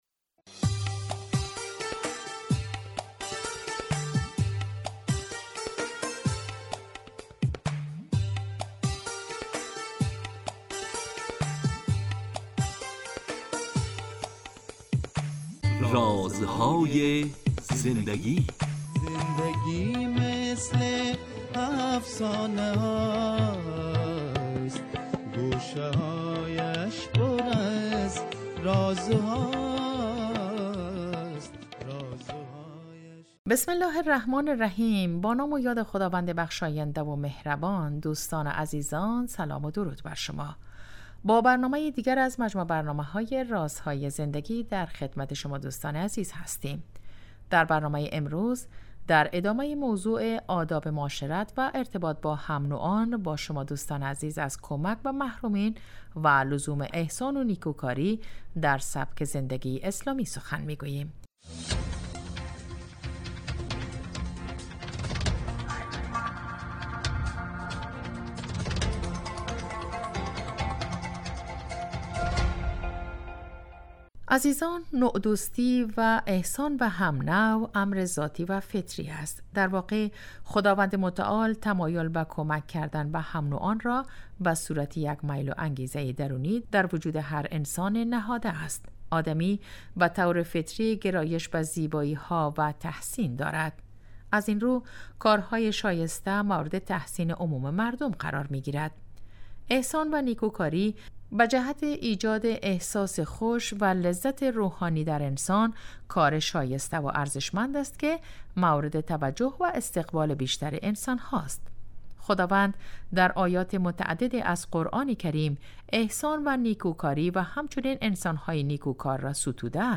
با مجموعه برنامه " رازهای زندگی" و در چارچوب نگاهی دینی به سبک زندگی با شما هستیم. این برنامه به مدت 15 دقیقه هر روز ساعت 11:35 به وقت افغانستان از رادیو دری پخش می شود .